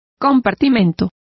Complete with pronunciation of the translation of compartment.